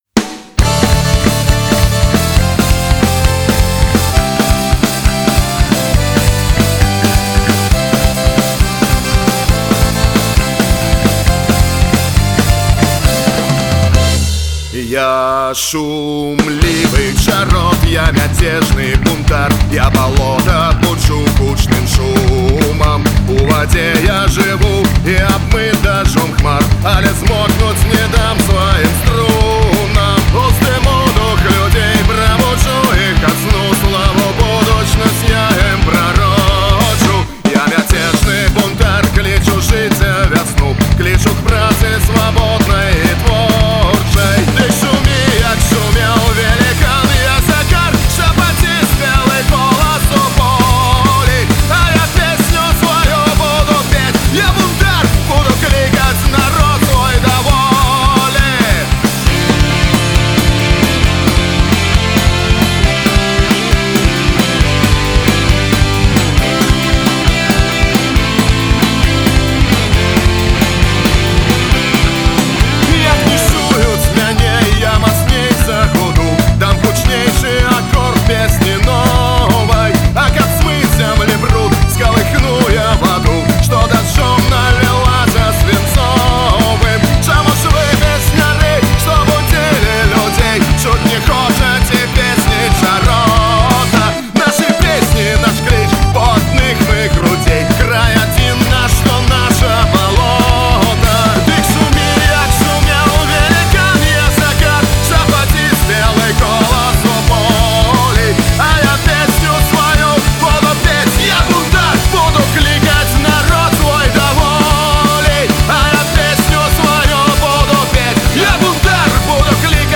Фольк-панк гурт заснаваны ў 2012 ў Горадні.